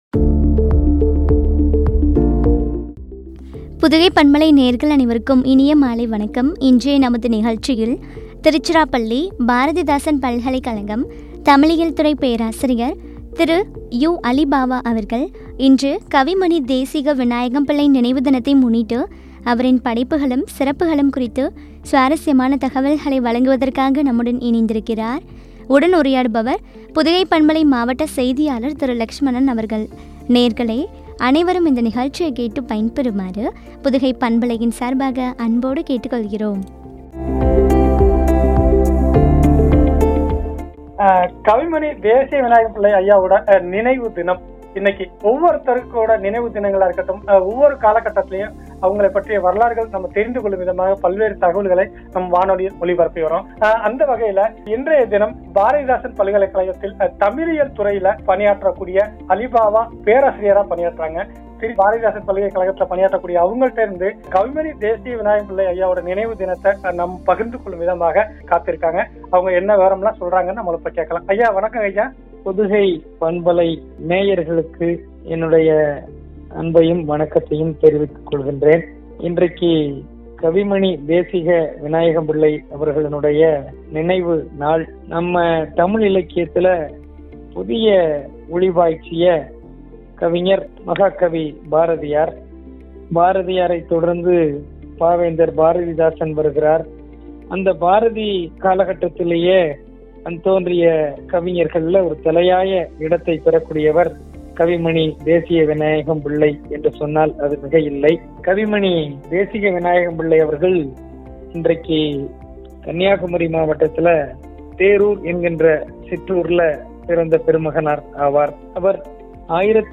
சிறப்புகளும் குறித்து வழங்கிய உரையாடல்.